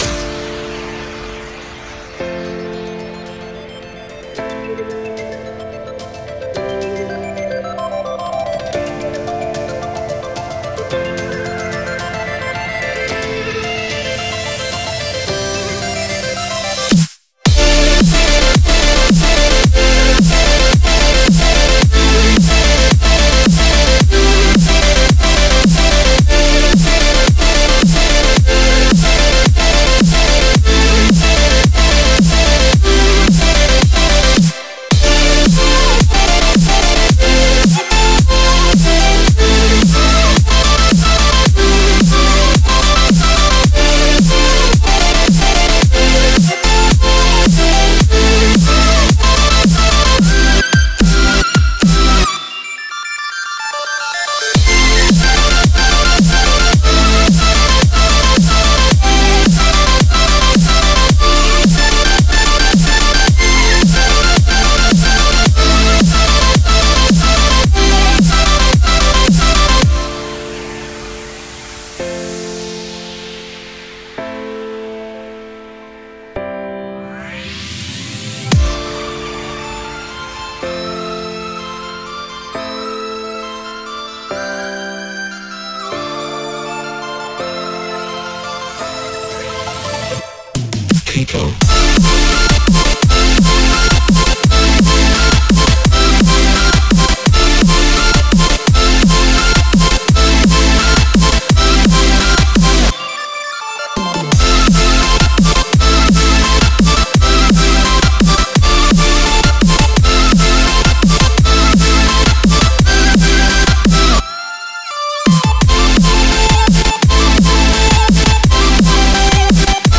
BPM55-170
Audio QualityPerfect (High Quality)
Req L: Song BPM 110 and 170.